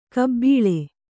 ♪ kbbīḷe